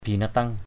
/bi-na-tʌŋ/
binateng.mp3